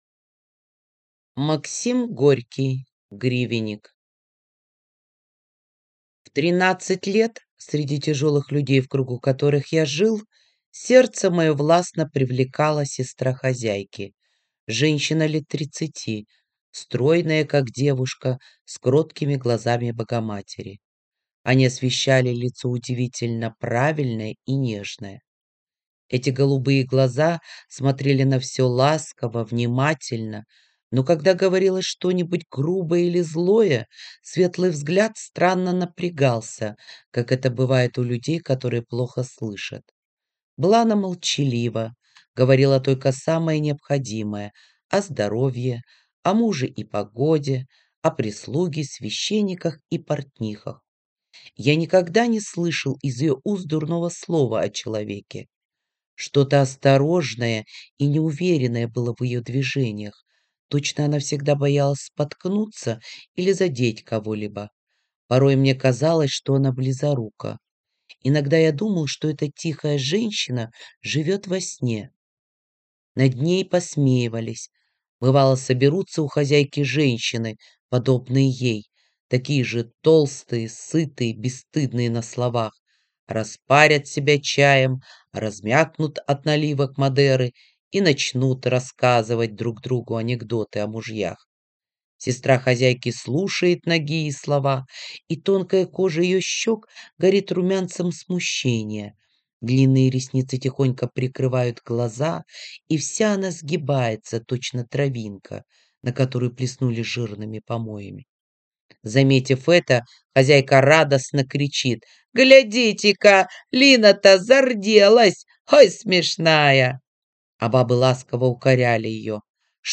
Аудиокнига Гривенник | Библиотека аудиокниг